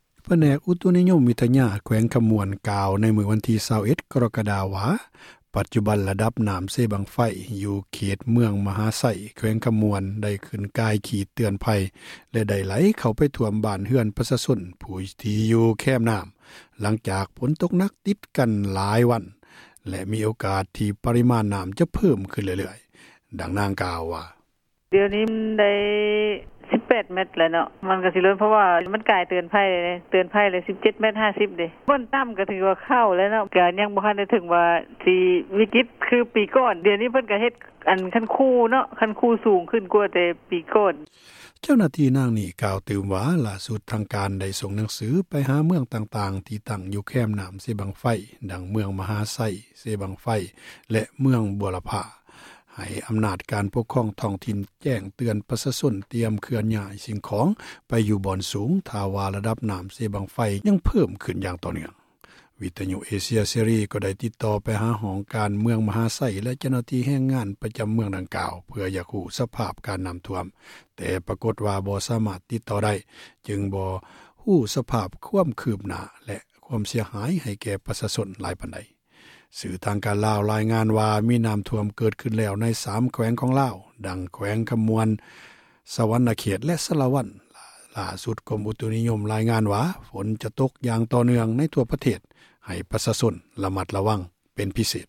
ນ້ຳເຊບັ້ງໄຟລົ້ນຝັ່ງ — ຂ່າວລາວ ວິທຍຸເອເຊັຽເສຣີ ພາສາລາວ